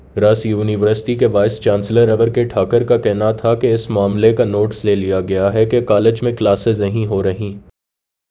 Spoofed_TTS/Speaker_11/106.wav · CSALT/deepfake_detection_dataset_urdu at main
deepfake_detection_dataset_urdu / Spoofed_TTS /Speaker_11 /106.wav